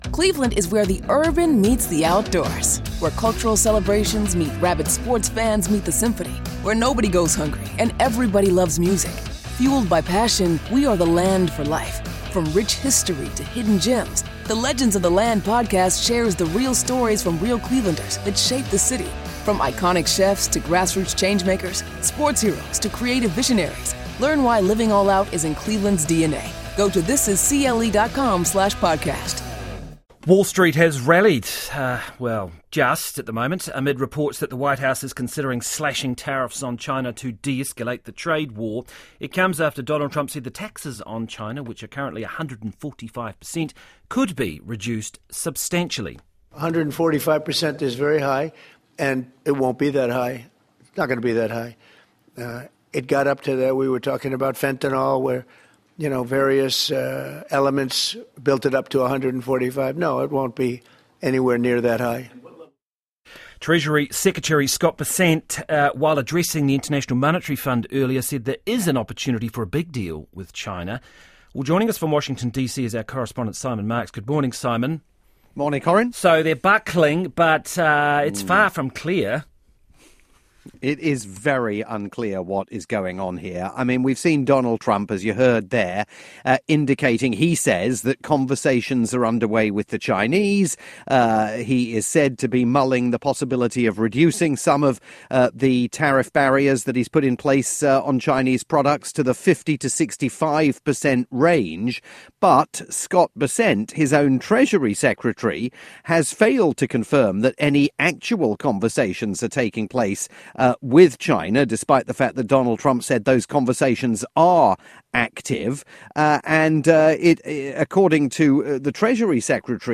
live update for Radio New Zealand's "Morning Report"